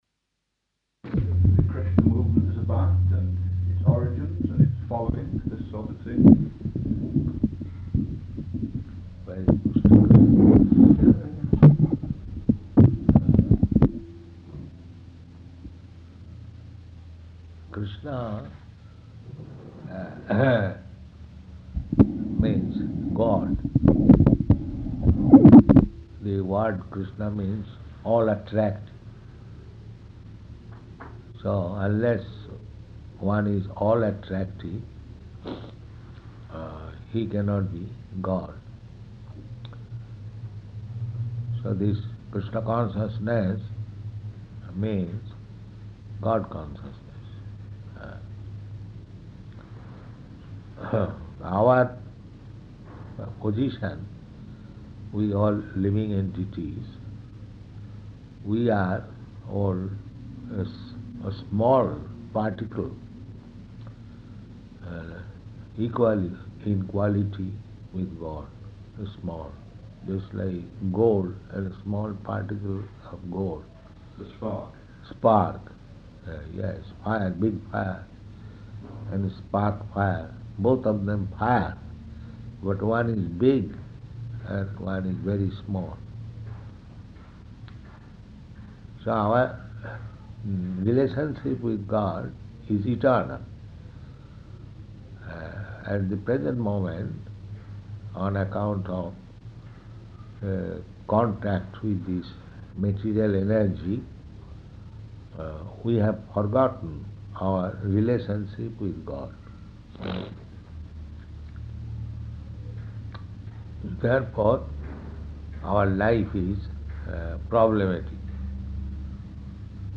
Type: Conversation
Location: Durban